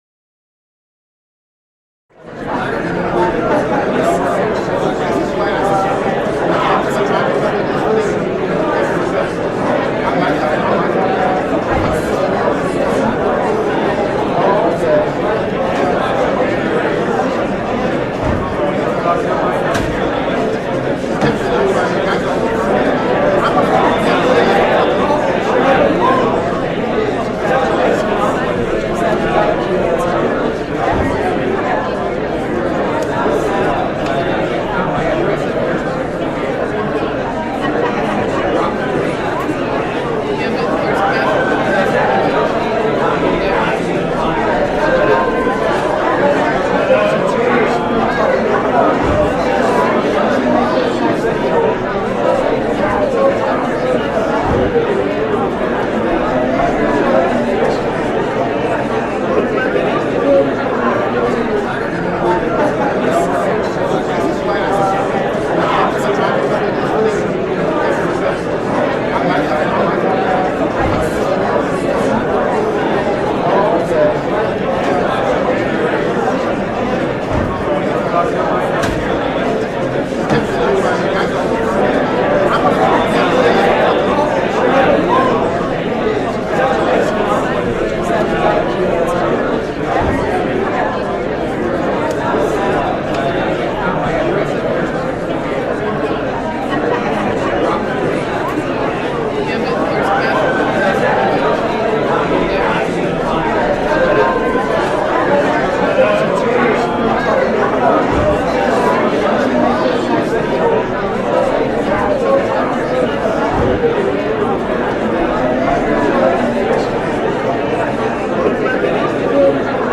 دانلود آهنگ همهمه از افکت صوتی انسان و موجودات زنده
دانلود صدای همهمه از ساعد نیوز با لینک مستقیم و کیفیت بالا
جلوه های صوتی